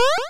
Add sound effect assets.
jump.wav